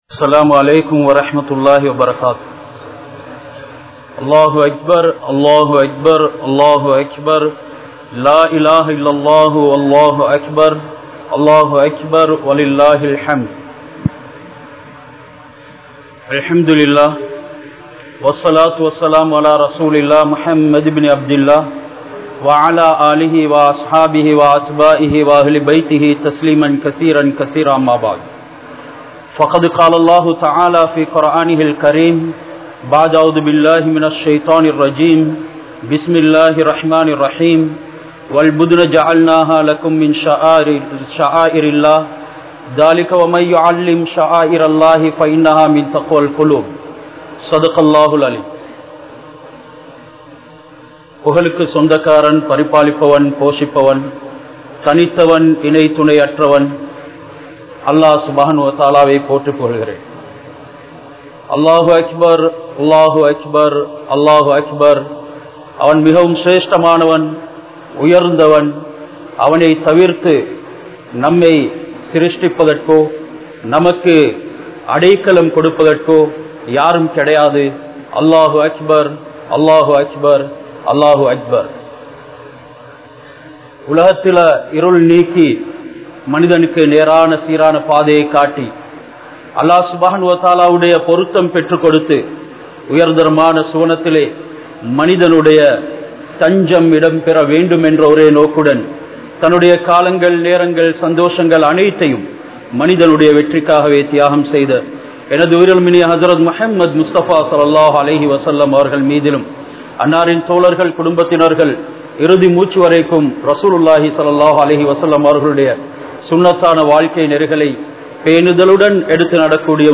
Ibrahim(Alai) Avarhalin Thiyaaham (இப்றாஹீம்(அலை) அவர்களின் தியாகம்) | Audio Bayans | All Ceylon Muslim Youth Community | Addalaichenai
Kattukela Jumua Masjith